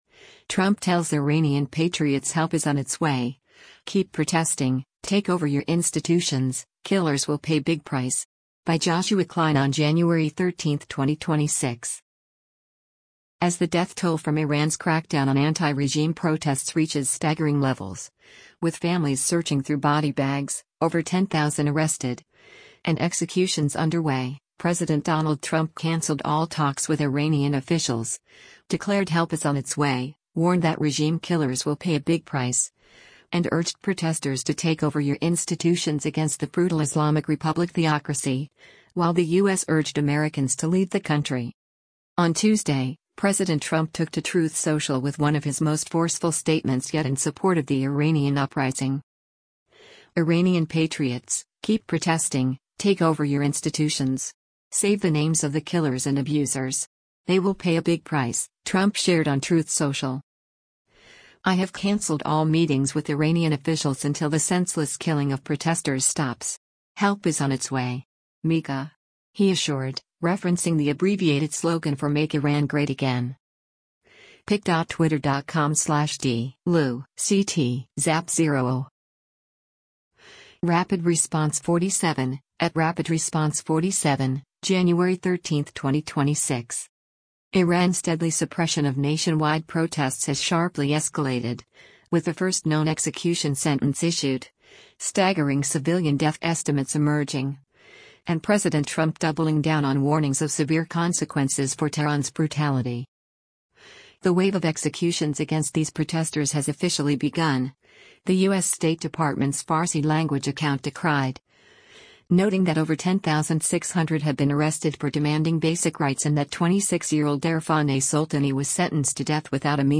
Later Tuesday afternoon, President Trump reiterated and expanded those warnings in public remarks in Detroit, Michigan, emphasizing that his message to Iran’s protesters — and to the regime overseeing the crackdown — was not confined to a morning Truth Social post but repeated again during a speech billed as focused on the economy following a tour of a Ford truck plant in nearby Dearborn.